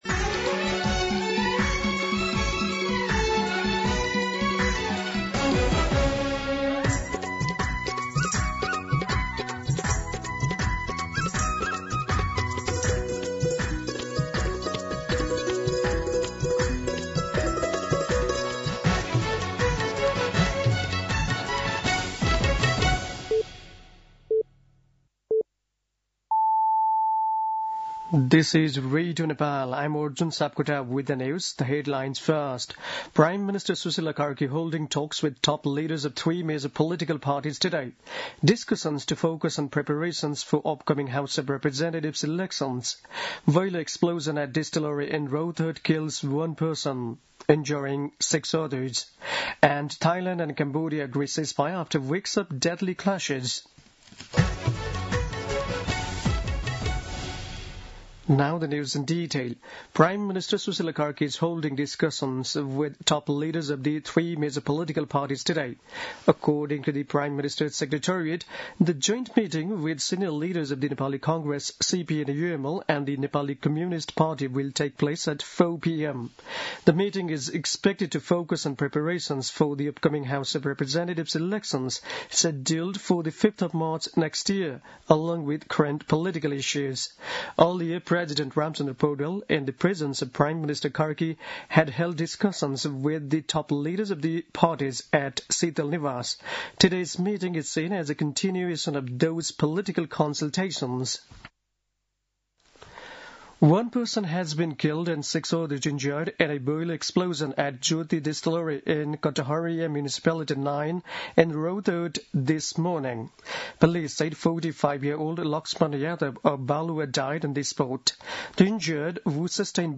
दिउँसो २ बजेको अङ्ग्रेजी समाचार : १२ पुष , २०८२
2-pm-English-News-5.mp3